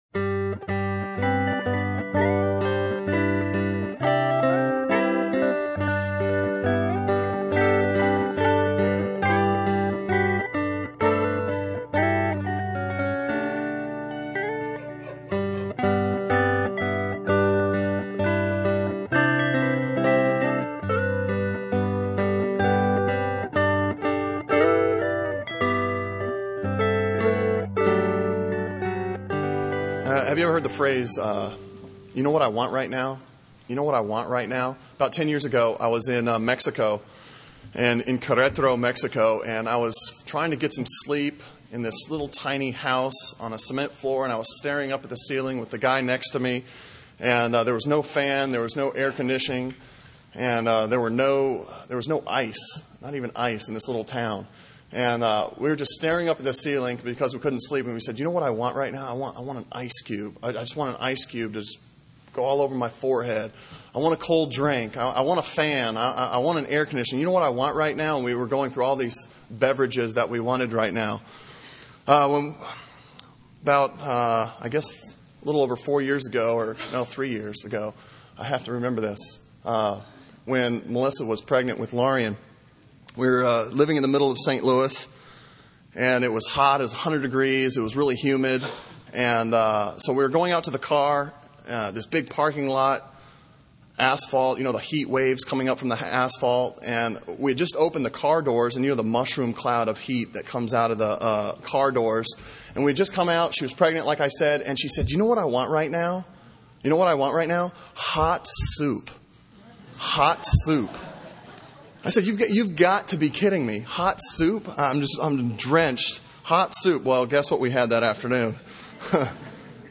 Sermon Audio from Sunday
Sermon on Ephesians 3:14-21 from October 21